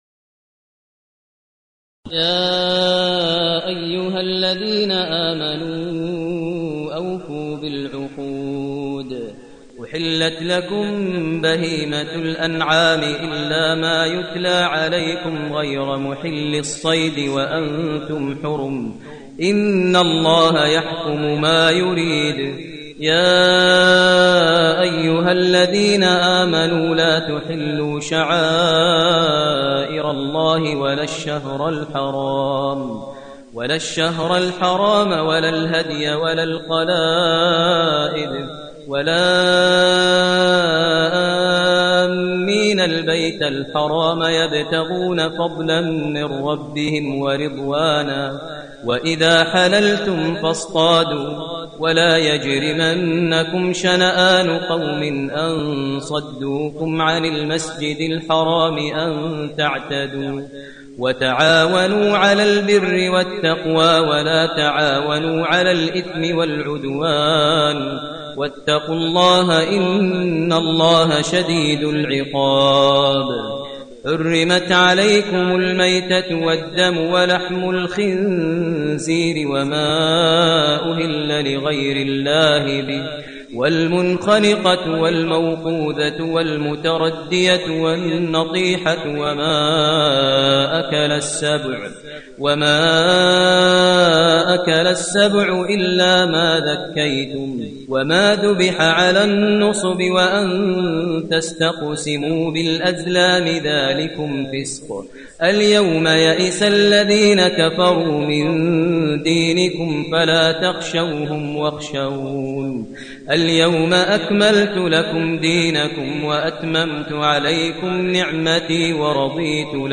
المكان: المسجد النبوي الشيخ: فضيلة الشيخ ماهر المعيقلي فضيلة الشيخ ماهر المعيقلي المائدة